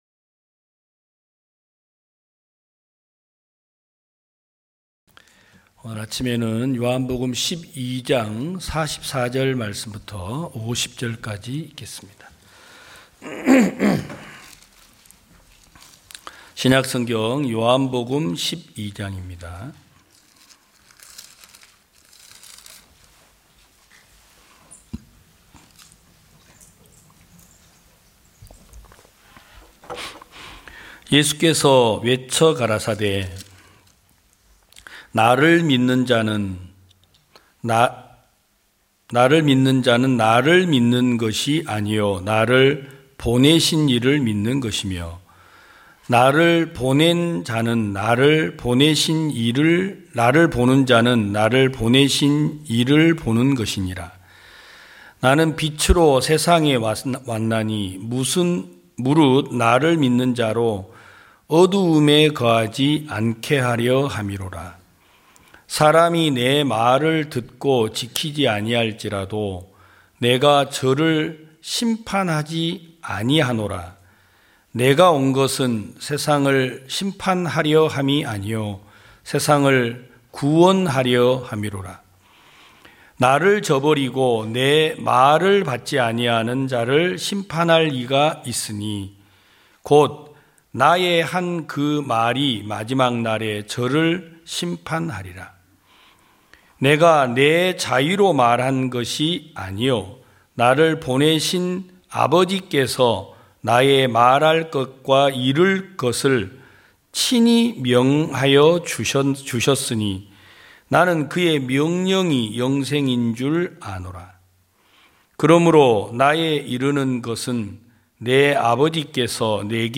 2022년 11월 20일 기쁜소식부산대연교회 주일오전예배
성도들이 모두 교회에 모여 말씀을 듣는 주일 예배의 설교는, 한 주간 우리 마음을 채웠던 생각을 내려두고 하나님의 말씀으로 가득 채우는 시간입니다.